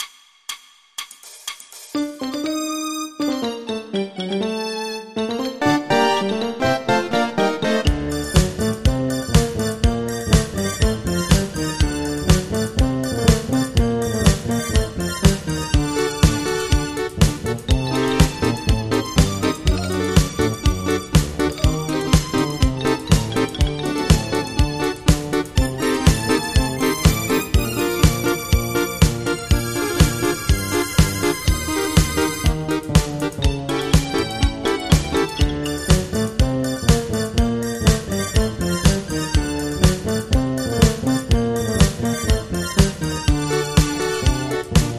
MIDI · Karaoke